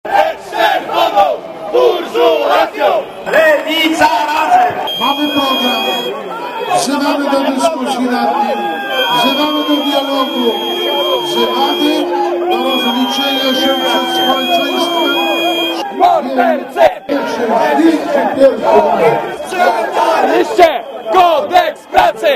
Manifestacja w Warszawie